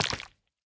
Minecraft Version Minecraft Version snapshot Latest Release | Latest Snapshot snapshot / assets / minecraft / sounds / block / honeyblock / break2.ogg Compare With Compare With Latest Release | Latest Snapshot
break2.ogg